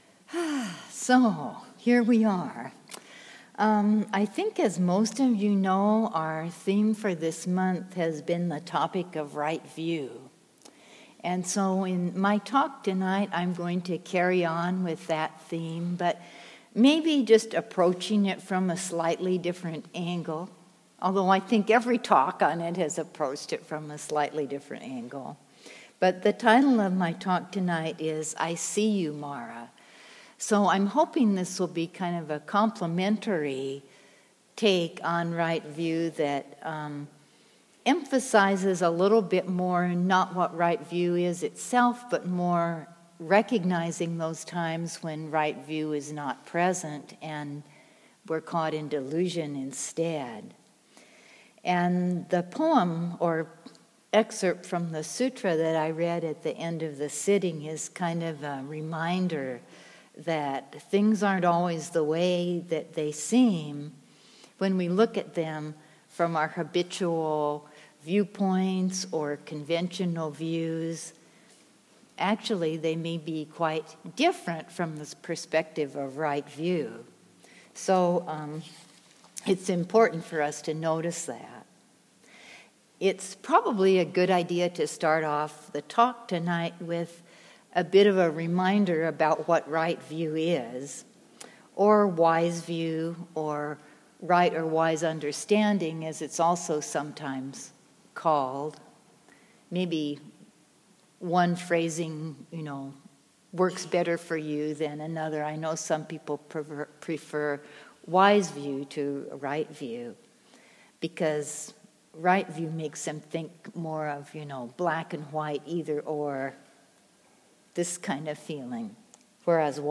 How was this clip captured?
2018-05-15 Venue: Seattle Insight Meditation Center